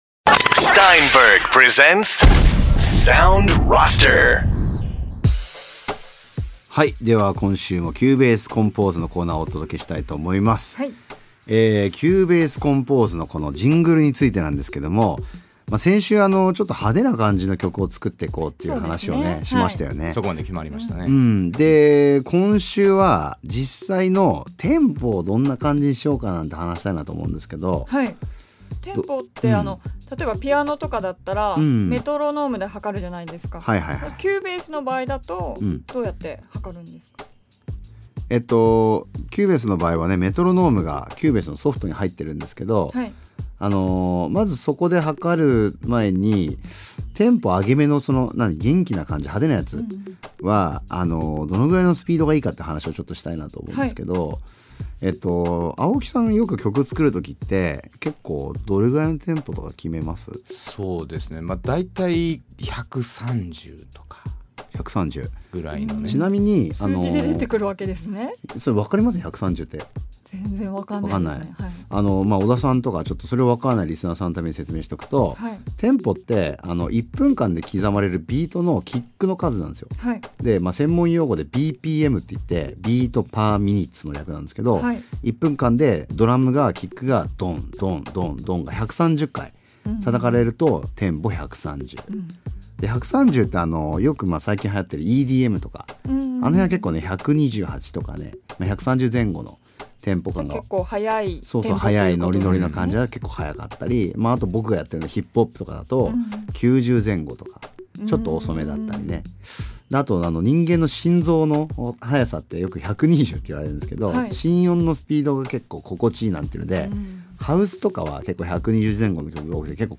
Steinberg が提供するラジオ番組「Sound Roster」。